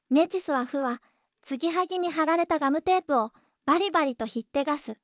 voicevox-voice-corpus
voicevox-voice-corpus / ROHAN-corpus /WhiteCUL_たのしい /ROHAN4600_0004.wav